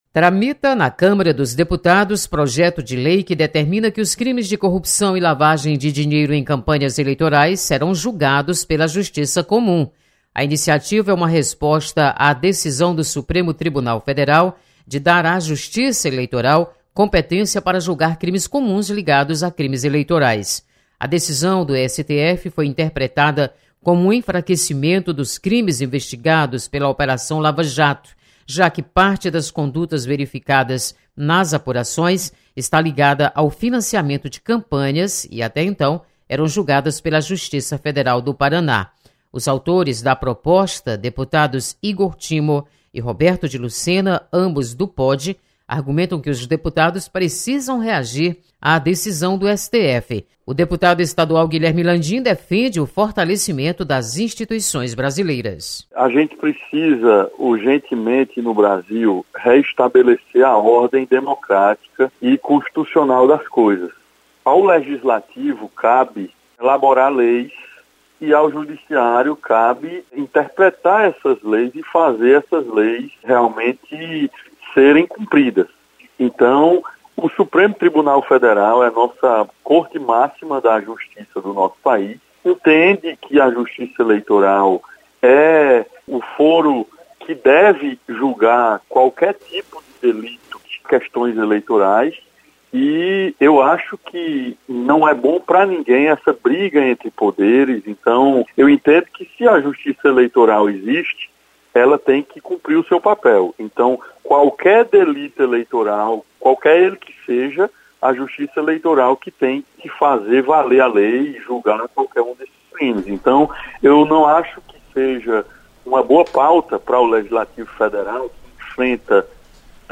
Deputados comentam decisão do STF sobre crimes eleitorais.